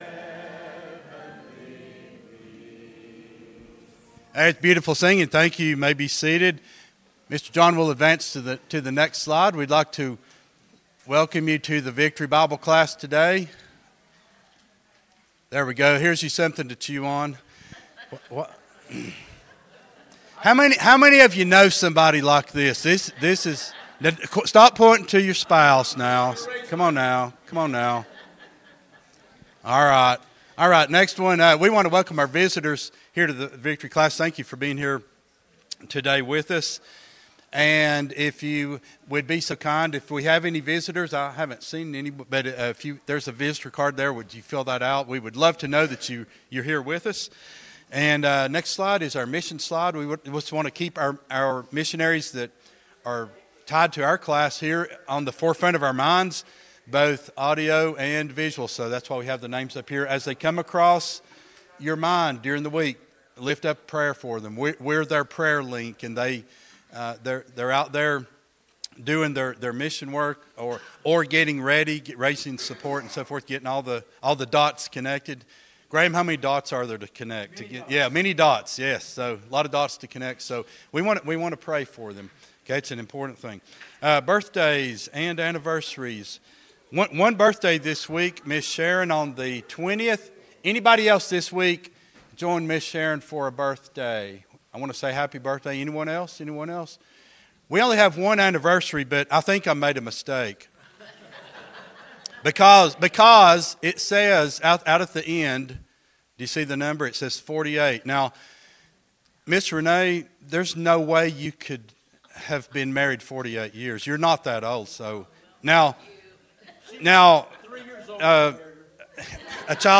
12-15-24 Sunday School Lesson | Buffalo Ridge Baptist Church